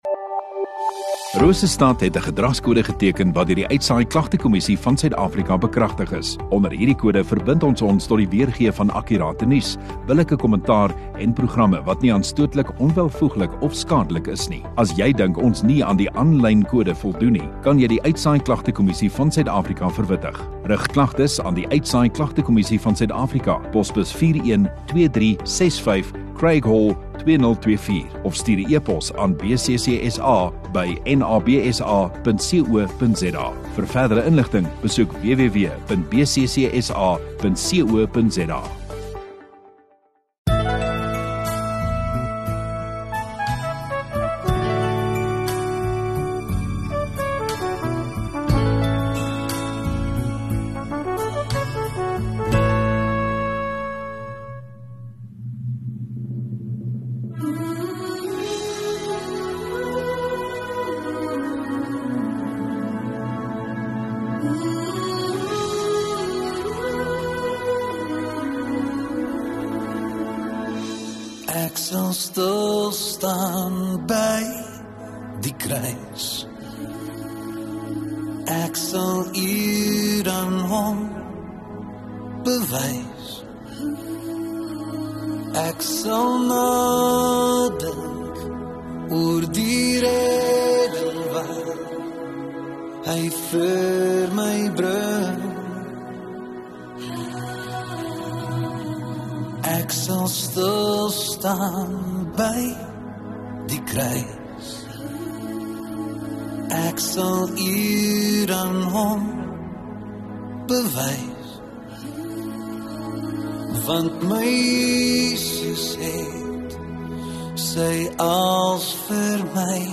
28 Jul Sondagaand Erediens